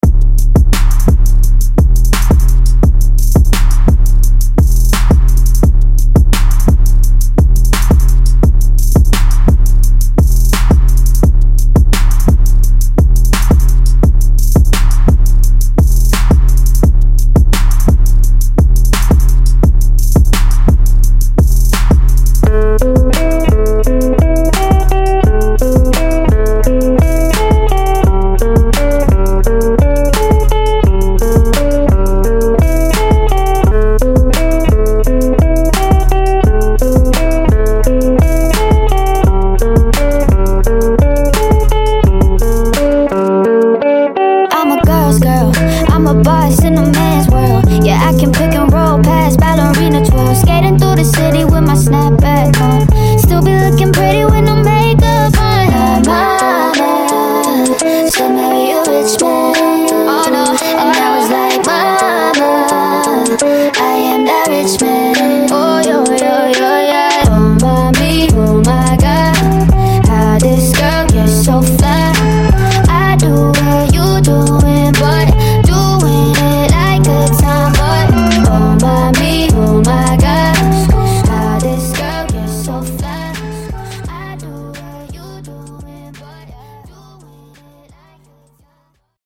Genre: 2000's Version: Clean BPM: 110 Time